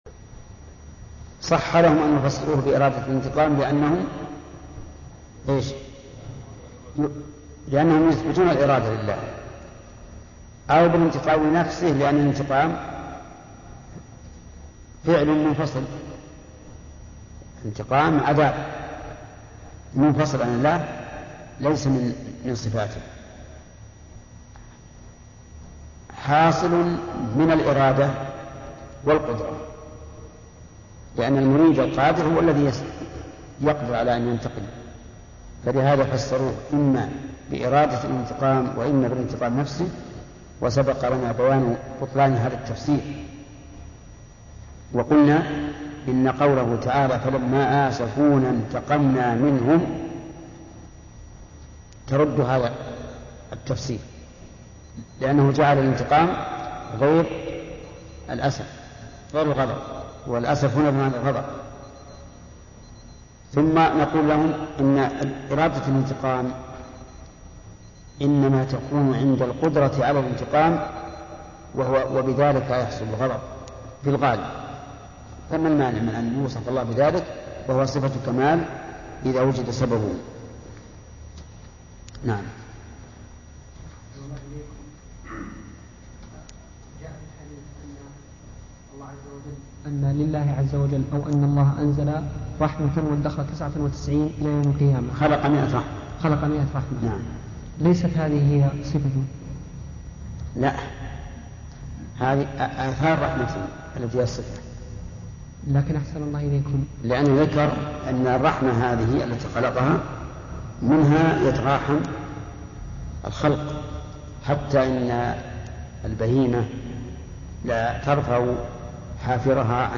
سلسلة شرح كتاب التوحيد _ الشيخ محمد بن صالح العثيمين